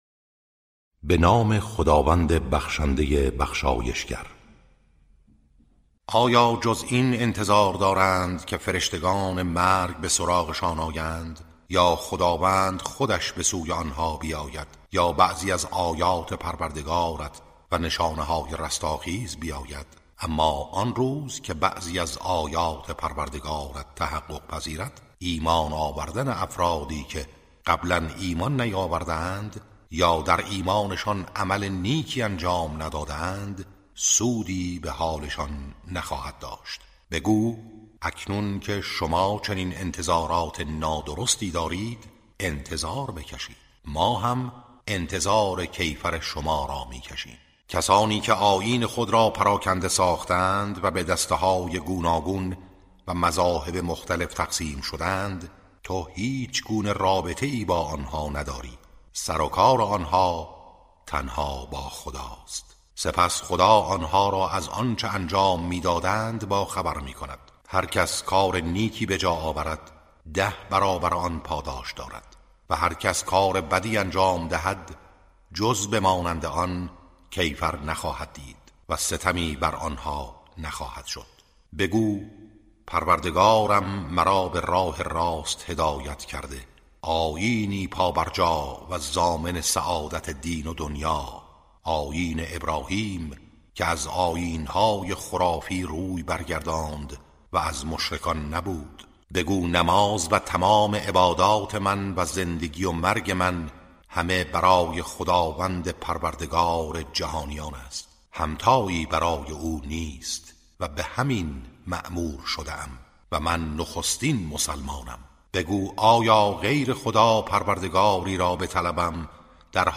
ترتیل صفحه 150 از سوره انعام(جزء هشتم)